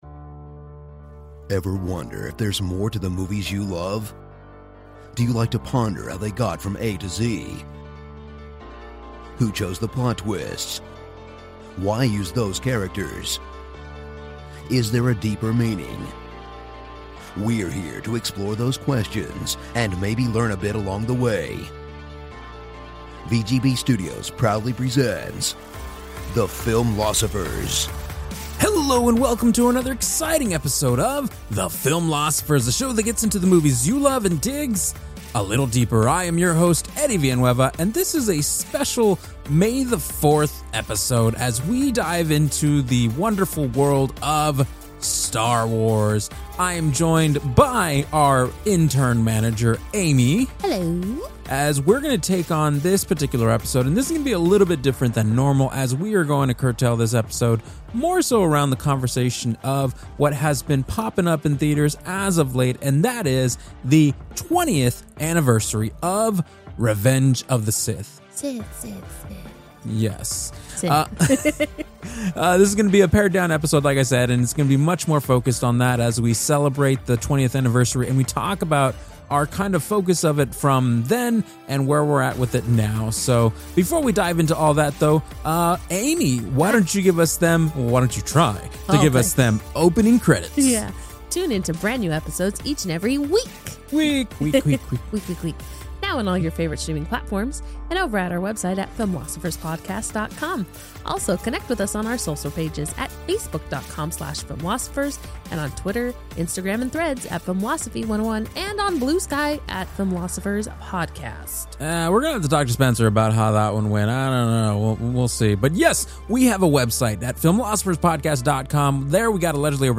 Lesson 427: Star Wars Day - Holy Sith! (Discussion)